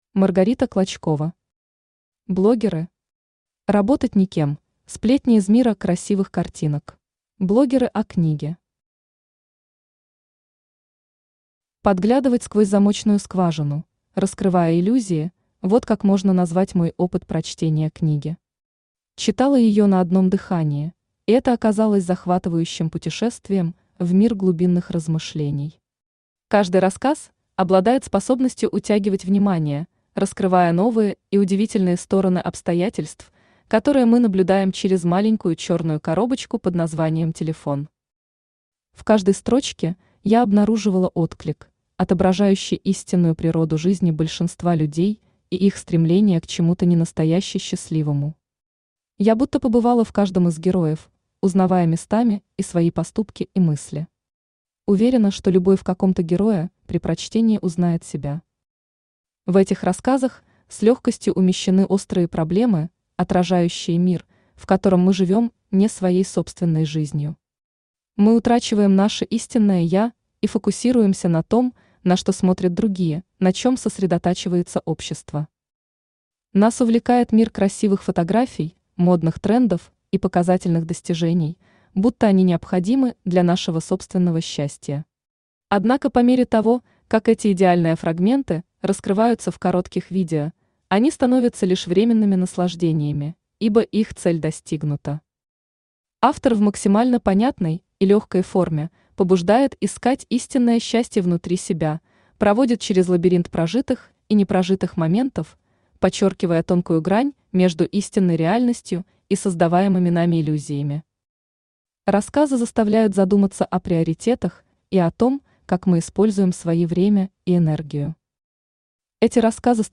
Работать никем: сплетни из мира красивых картинок Автор Маргарита Клочкова Читает аудиокнигу Авточтец ЛитРес.